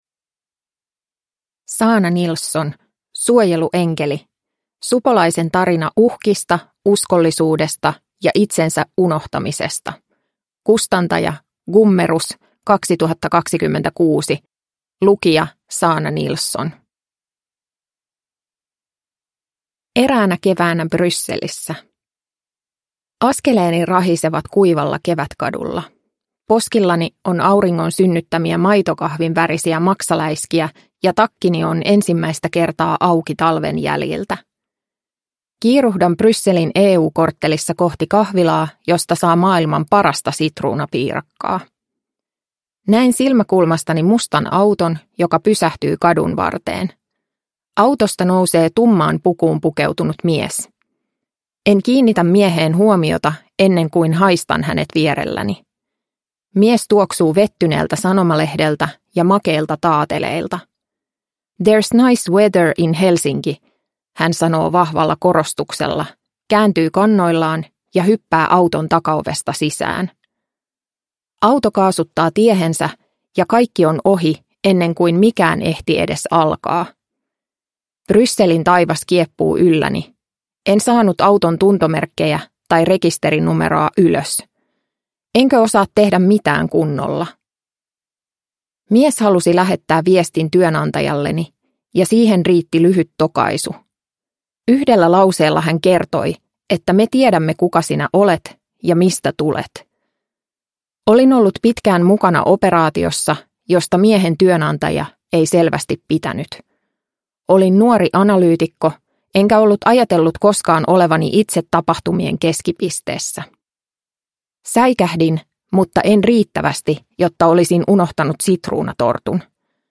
Suojeluenkeli – Ljudbok